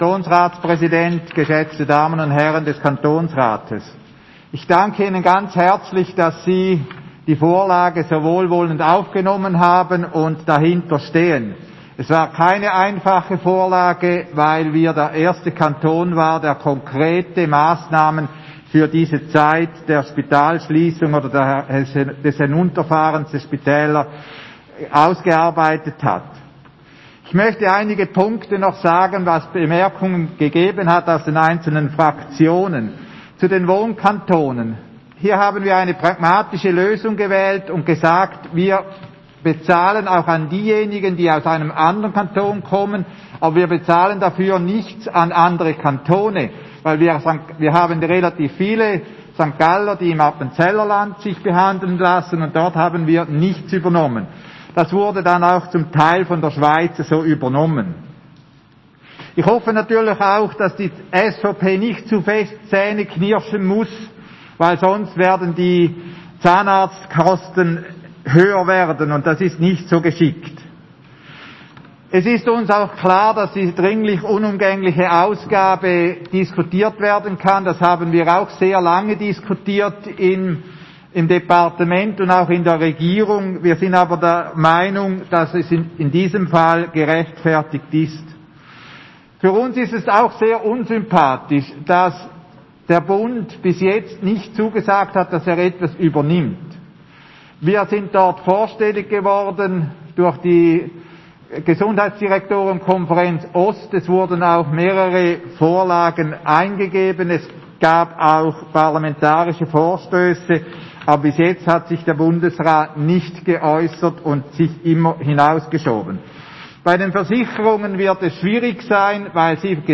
Session des Kantonsrates vom 15. bis 17. Februar 2021